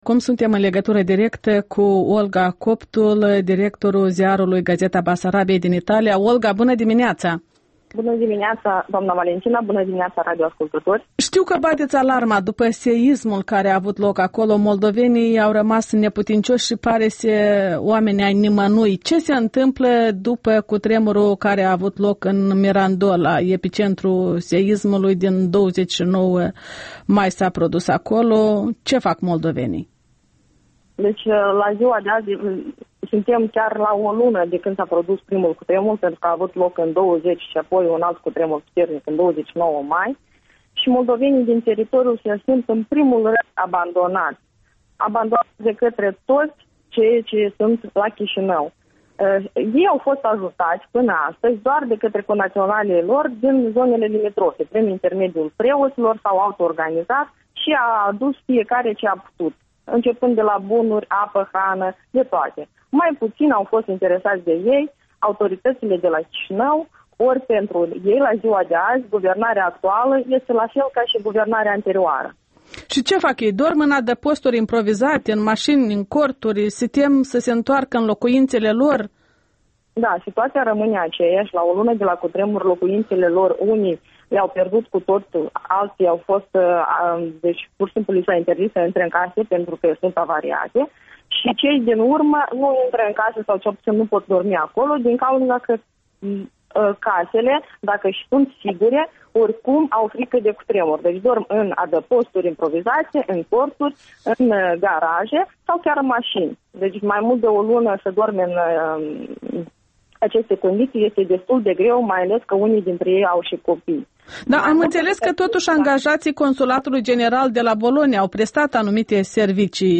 prin telefon, de la Bologna.